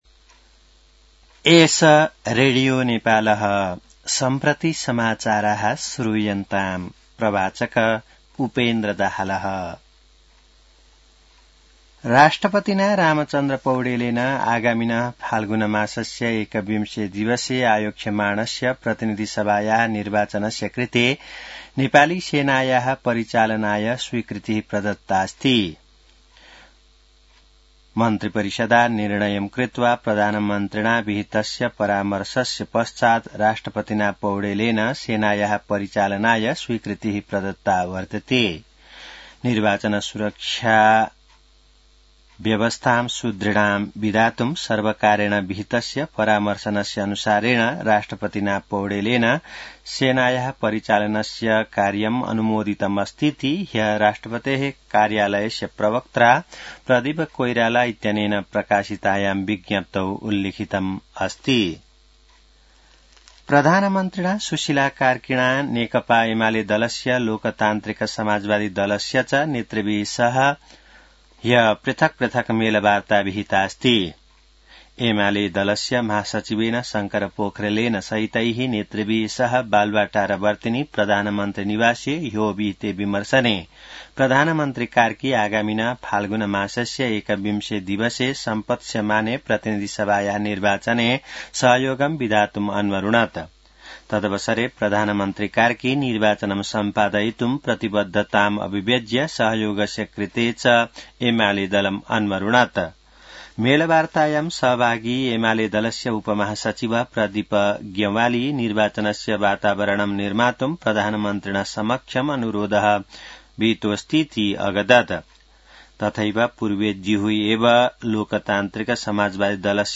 संस्कृत समाचार : १२ मंसिर , २०८२